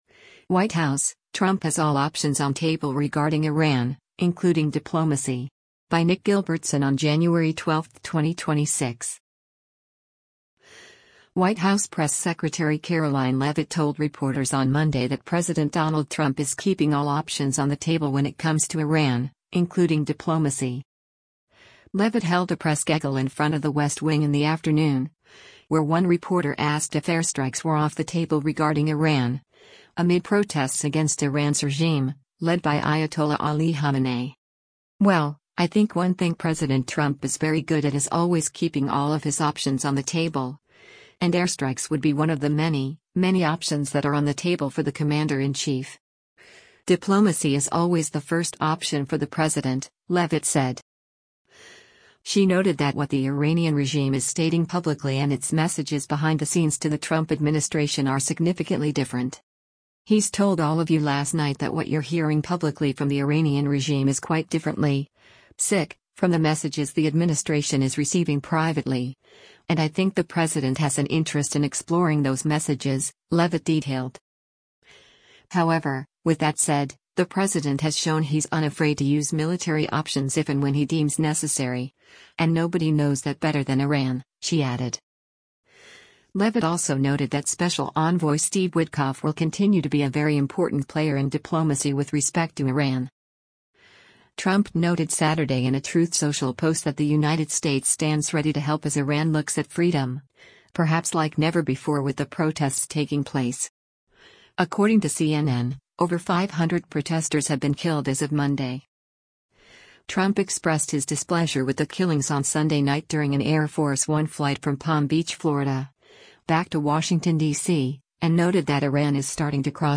Leavitt held a press gaggle in front of the West Wing in the afternoon, where one reporter asked if “airstrikes” were “off the table” regarding Iran, amid protests against Iran’s regime, led by Ayatollah Ali Khamenei.